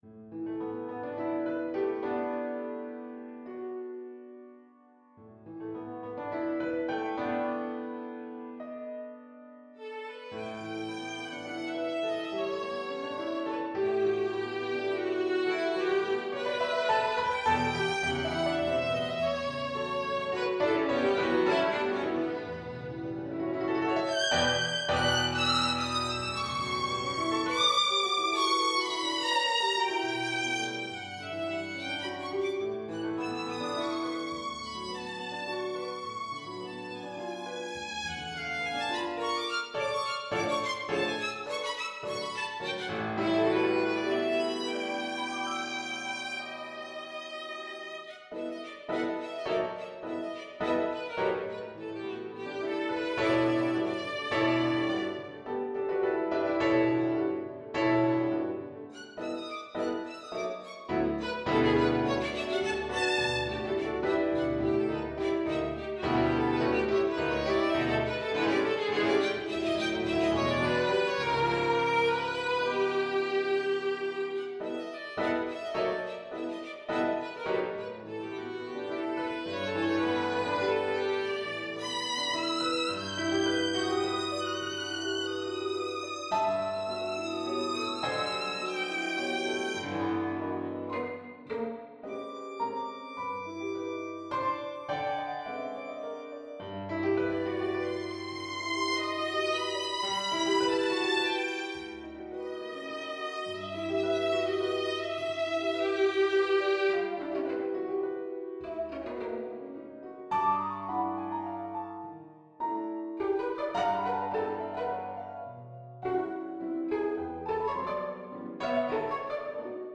Bacchante, for violin and piano